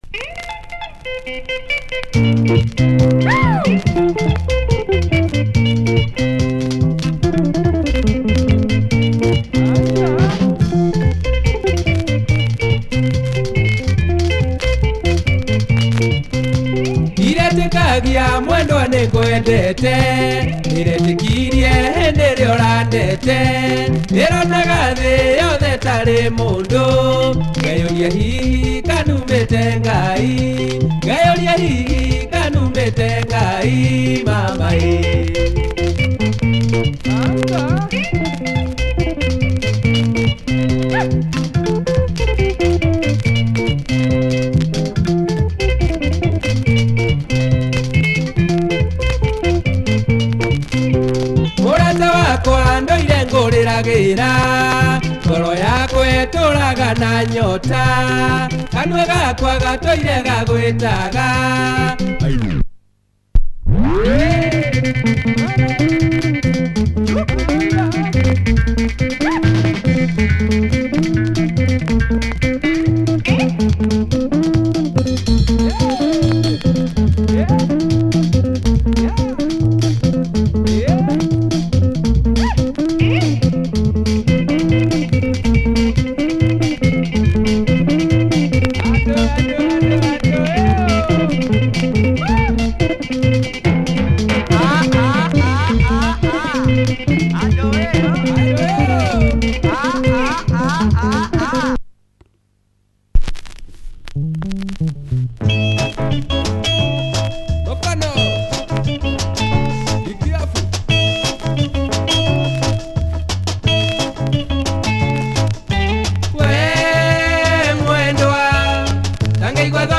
A great produced Kikuyu dancer
Super breakdown!